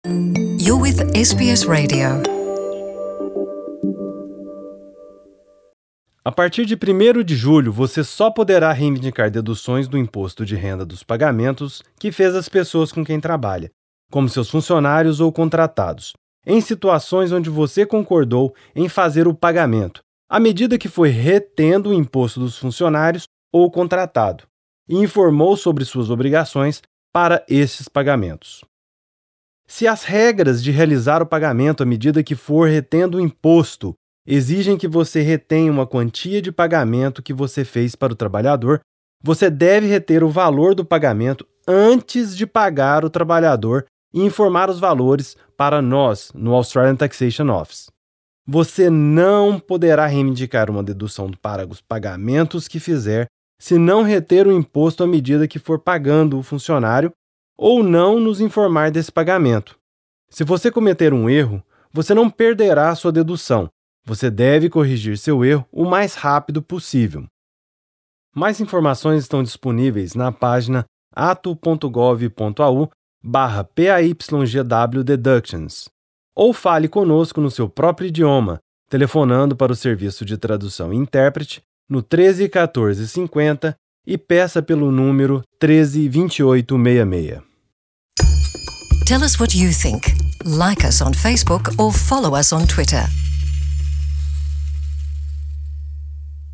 anúncio comunitário